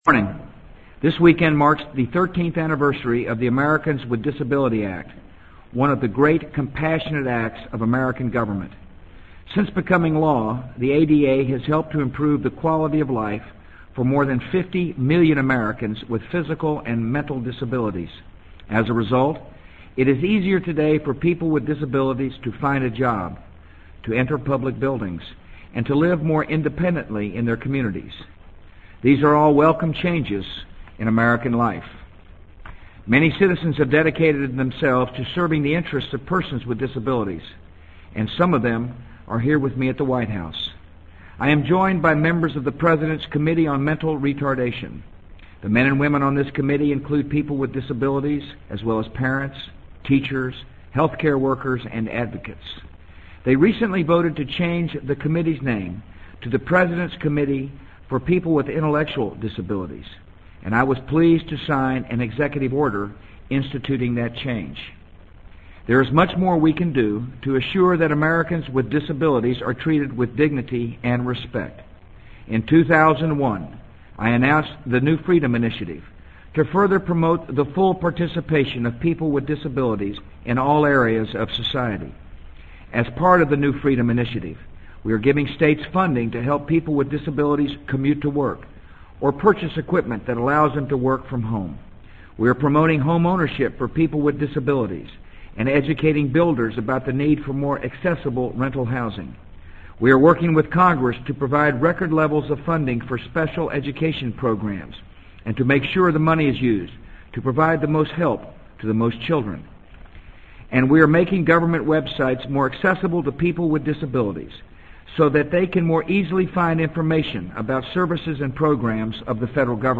【美国总统George W. Bush电台演讲】2003-07-26 听力文件下载—在线英语听力室